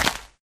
dig / grass3